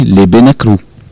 Localisation Soullans
Catégorie Locution